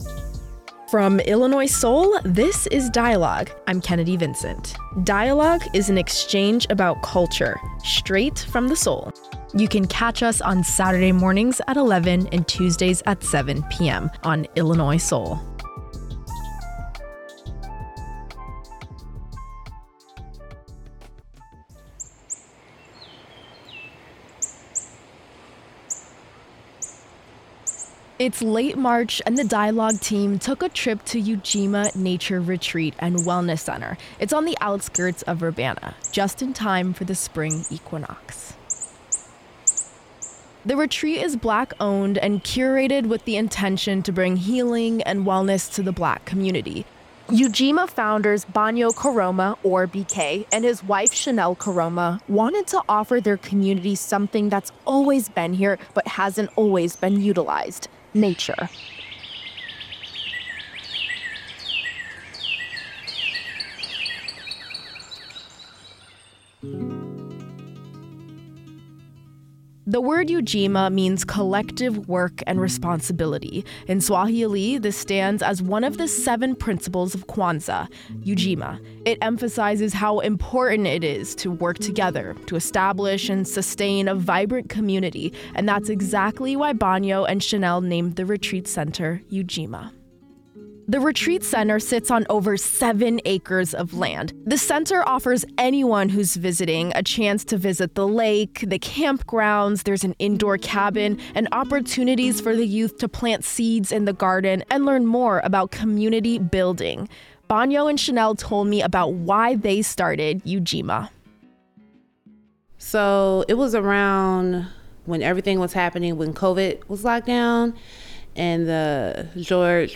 How can rest be a form of resistance? We sat down with Ujima Retreat Center in Urbana and discussed how they are reclaiming nature.